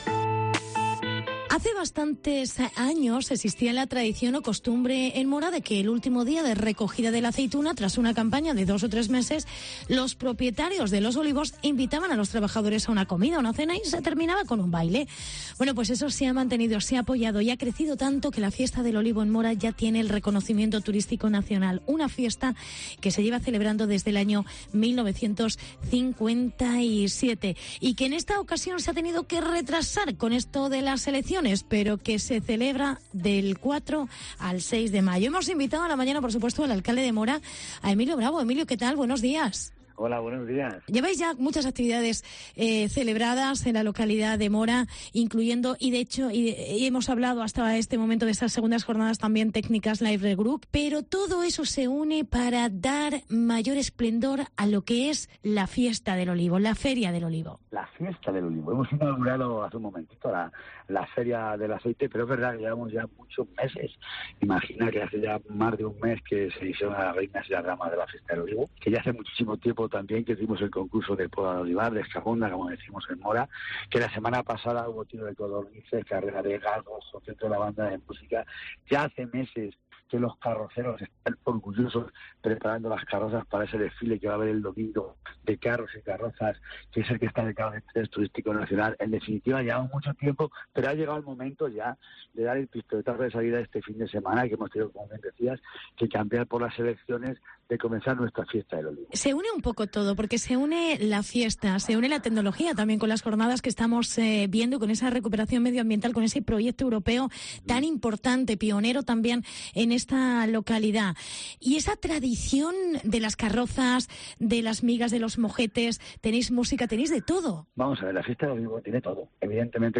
LXIII Fiesta del Olivo en Mora. Entrevista alcalde Emilio Bravo